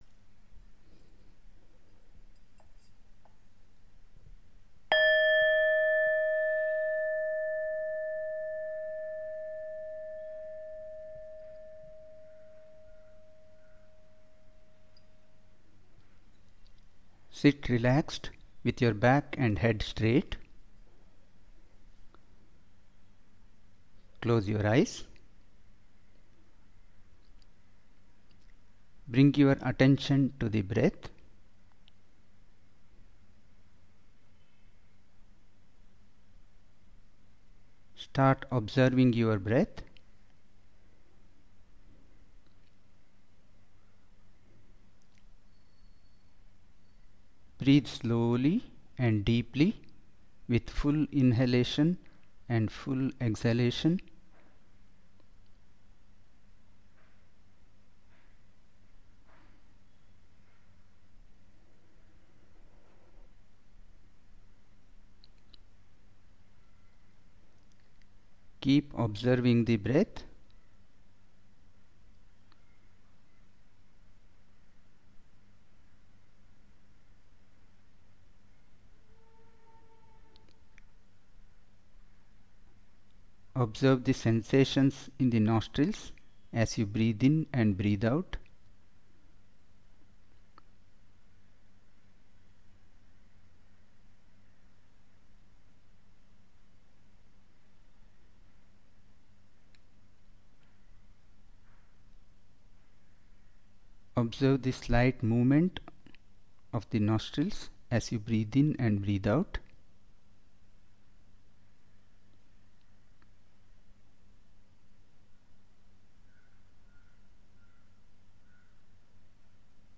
Audio Instructions